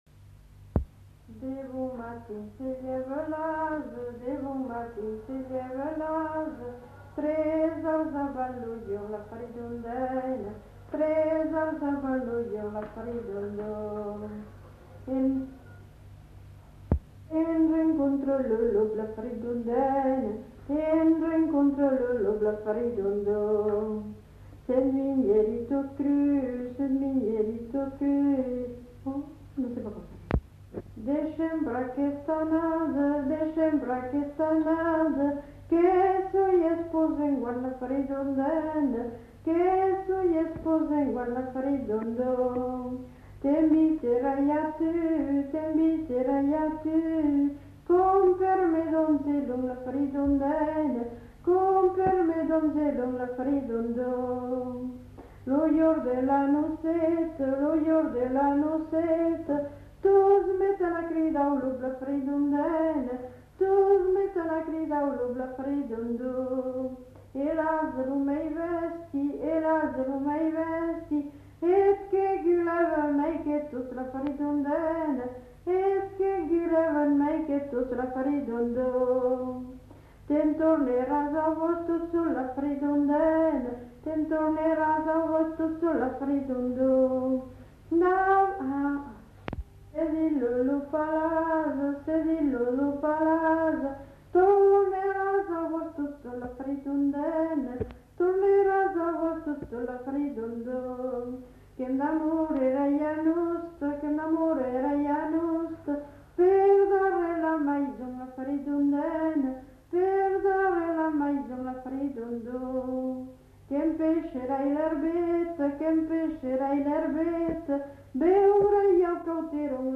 [Brocas. Groupe folklorique] (interprète)
Aire culturelle : Marsan
Genre : chant
Effectif : 1
Type de voix : voix de femme
Production du son : chanté